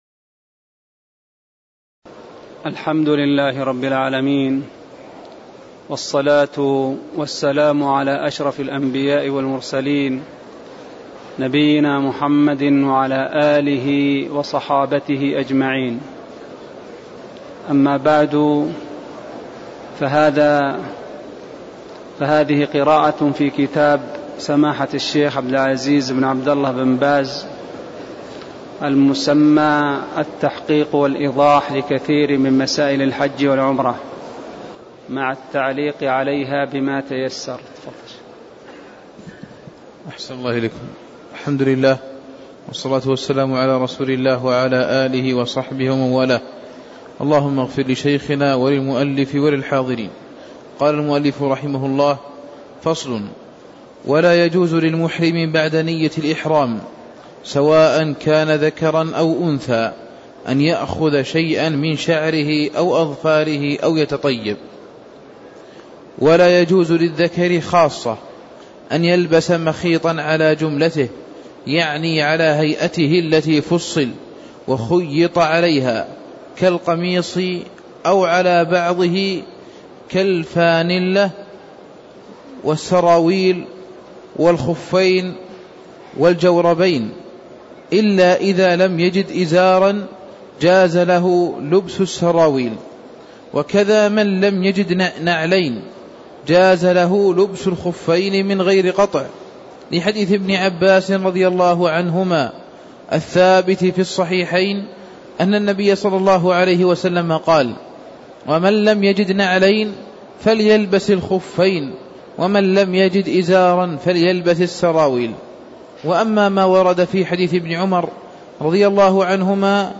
تاريخ النشر ١٦ محرم ١٤٣٦ هـ المكان: المسجد النبوي الشيخ: فضيلة الشيخ عبدالله بن محمد آل خنين فضيلة الشيخ عبدالله بن محمد آل خنين من قوله: ولا يجوز للمحرم بعد نيّة الإحرام (03)